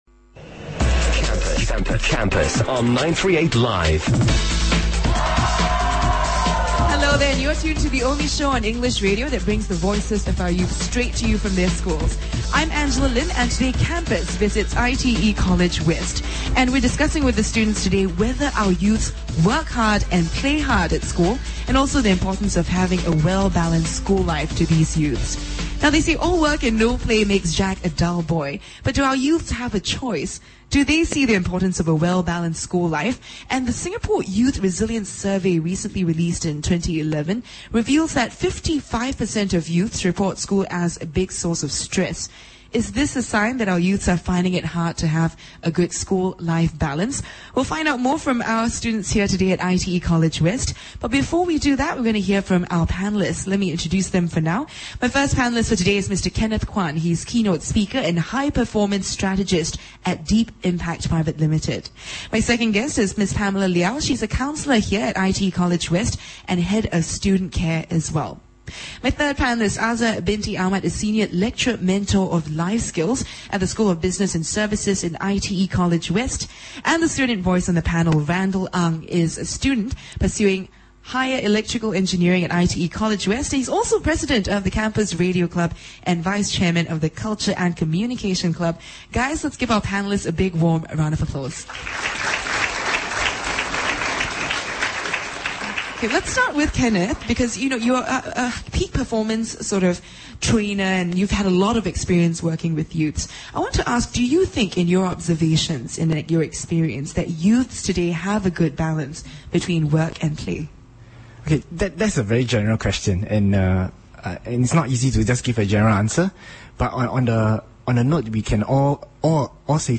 If you missed the live broadcast, you can listen to it again here:
Students from the audience also weigh in their opinions and tips on managing school-life balance.